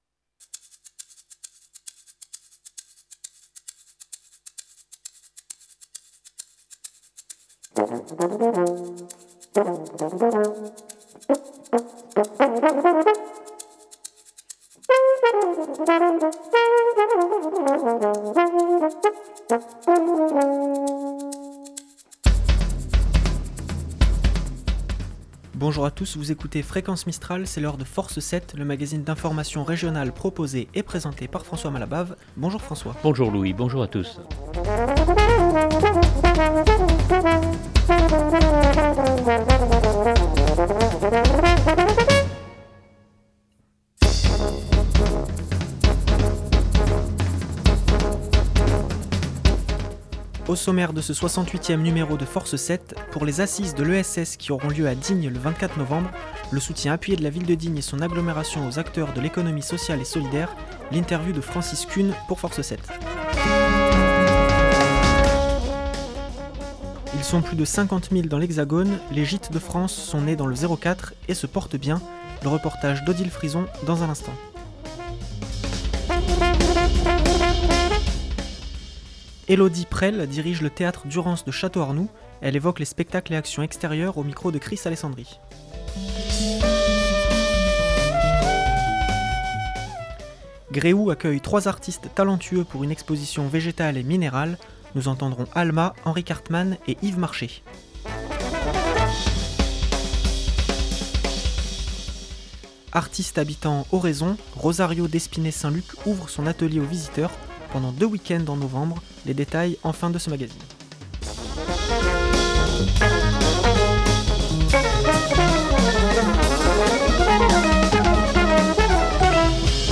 un magazine d’information régional